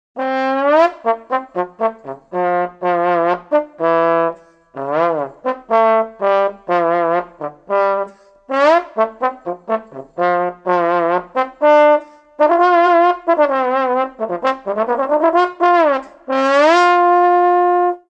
Die Posaune